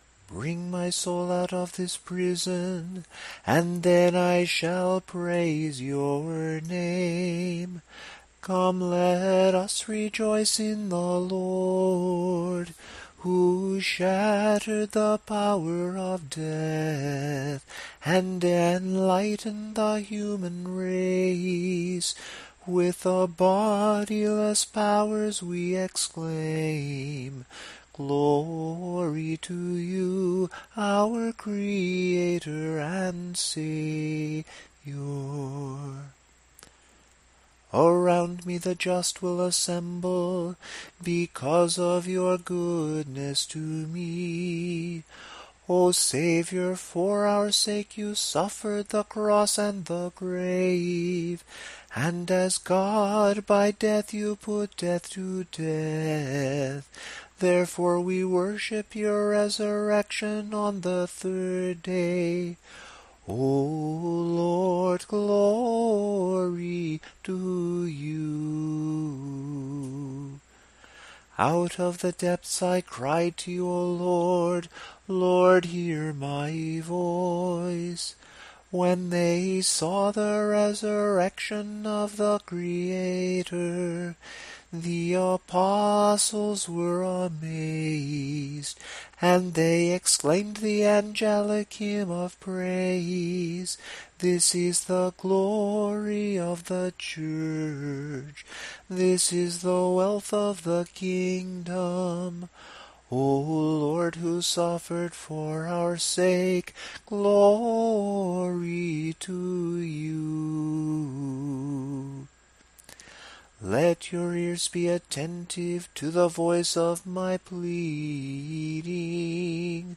After a series of recited (i.e., simply chanted) verses, we begin singing the stichera. Here are the first four Sunday stichera of the Resurrection in Tone 7, together with the psalm verses that would ordinarily come before each one..
Even though the verse begins on a reciting tone, it starts on mi, not do!
Tone_7_samohlasen_Sunday_stichera.mp3